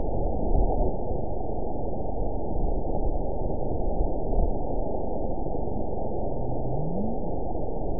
event 912486 date 03/27/22 time 14:34:34 GMT (3 years, 1 month ago) score 9.53 location TSS-AB05 detected by nrw target species NRW annotations +NRW Spectrogram: Frequency (kHz) vs. Time (s) audio not available .wav